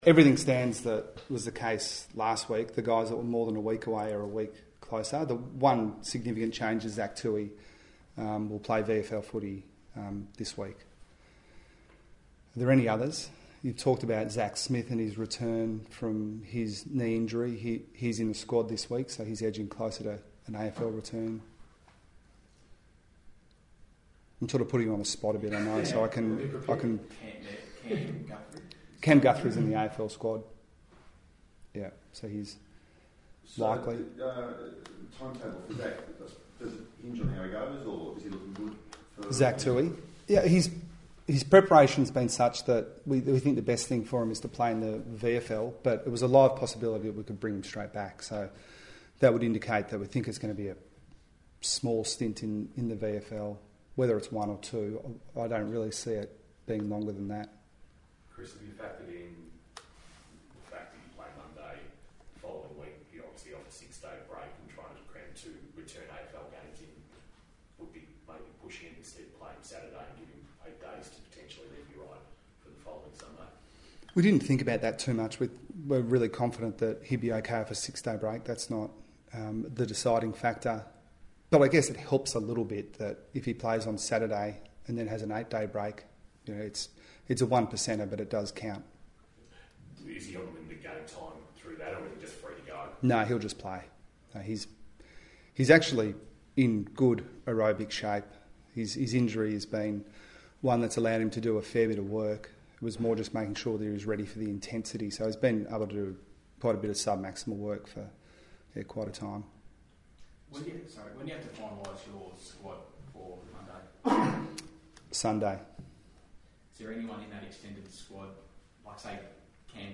Geelong coach Chris Scott faces the media ahead of the Easter Monday clash with Hawthorn.